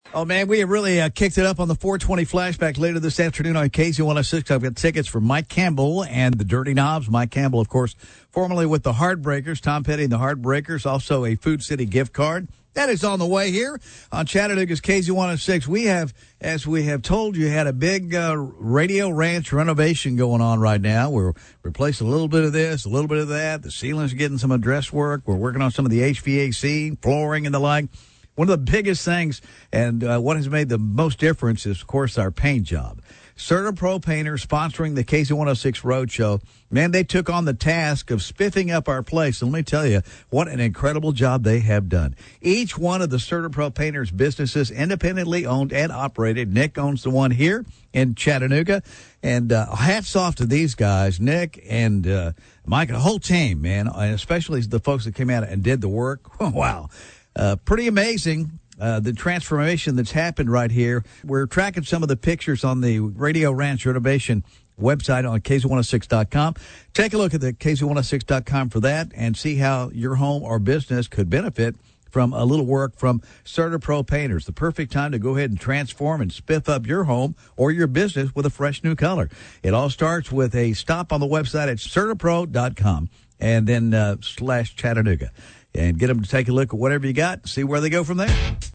Radio Spots on KZ 106